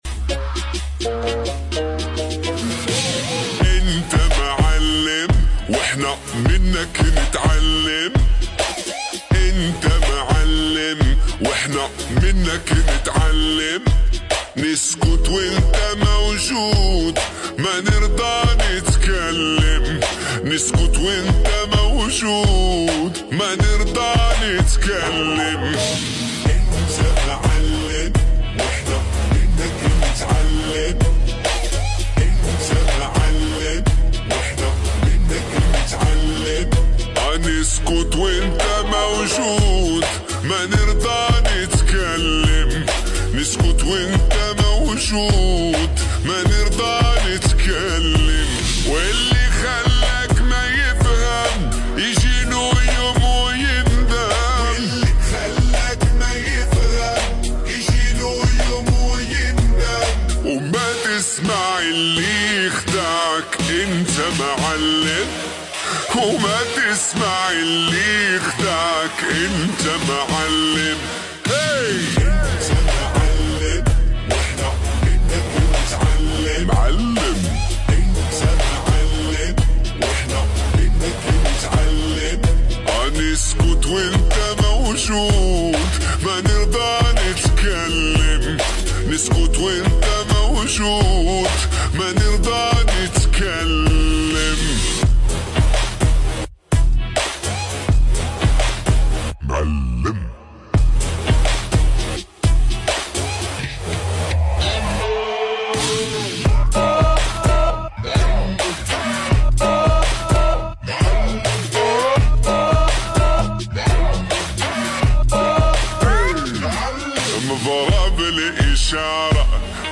SLOWED AND REVERB